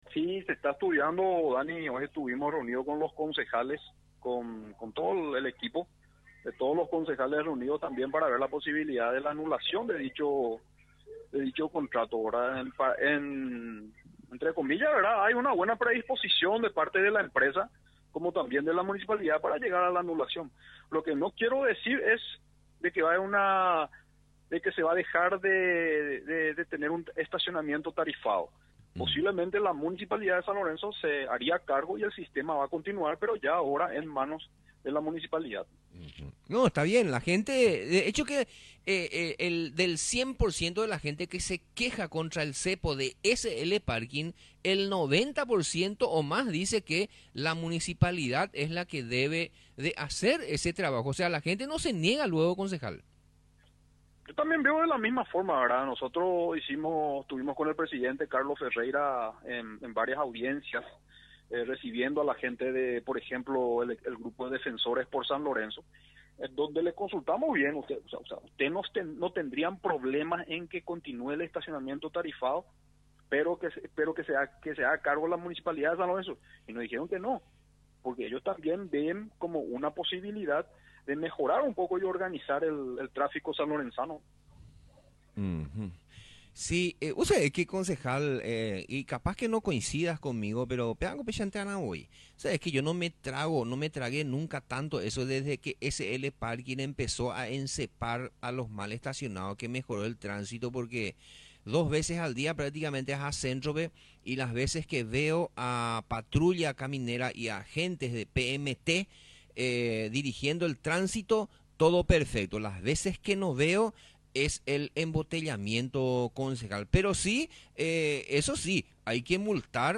LO QUE DIJO EL EDIL FEDERICO FRANCO